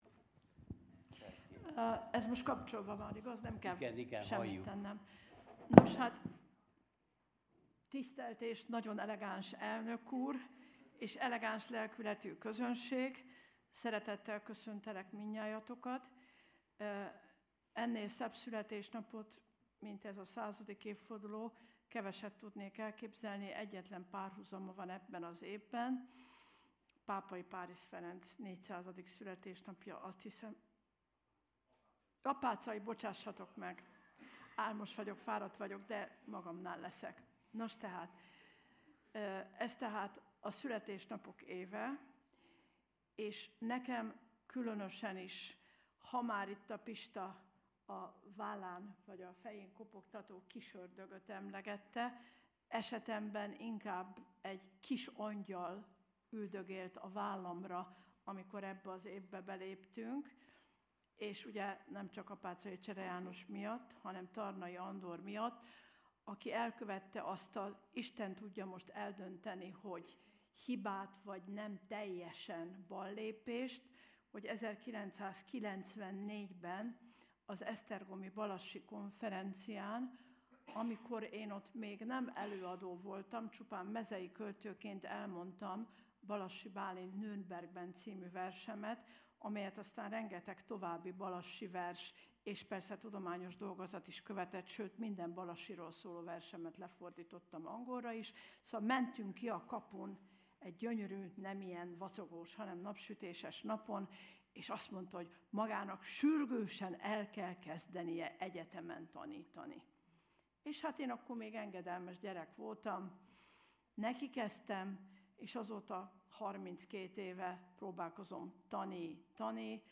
Hagyományőrzés és önbecsülés. Száz éve született Tarnai Andor , Hatodik ülés